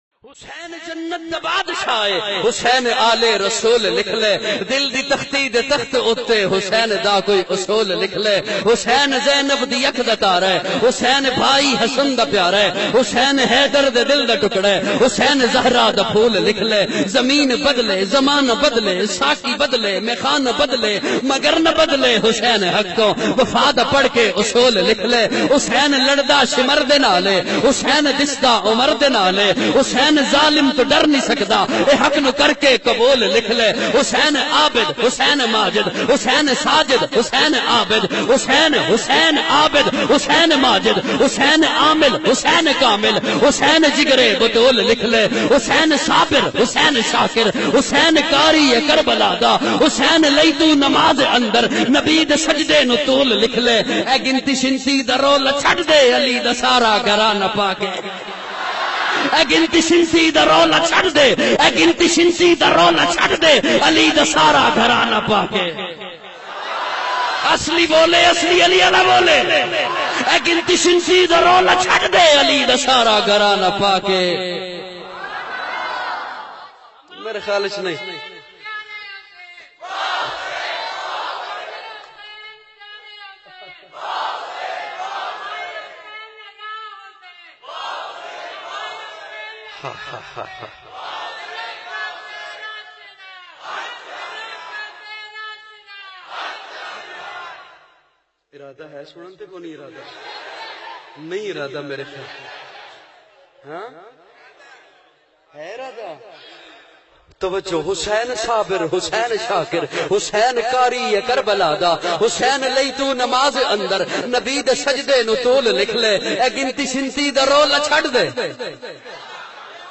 Amazing Shan e Hussain R.A bayan mp3